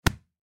دانلود آهنگ دعوا 9 از افکت صوتی انسان و موجودات زنده
جلوه های صوتی
دانلود صدای دعوای 9 از ساعد نیوز با لینک مستقیم و کیفیت بالا